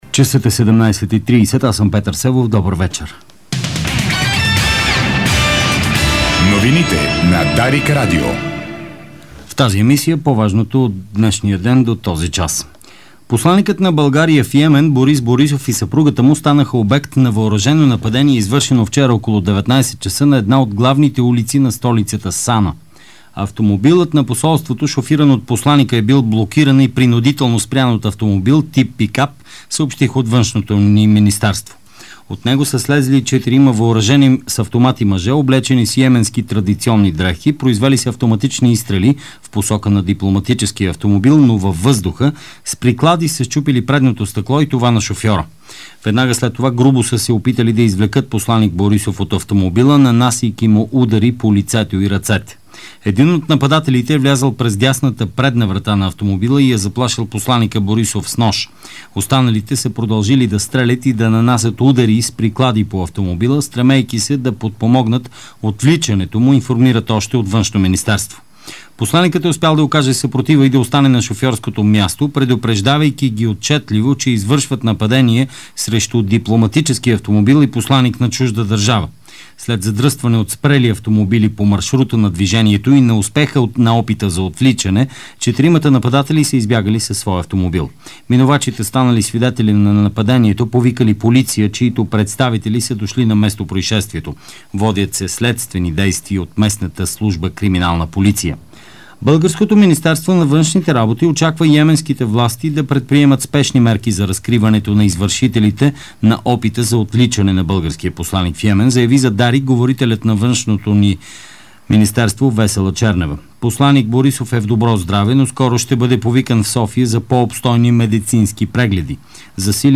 Обзорна информационна емисия - 13.05.2012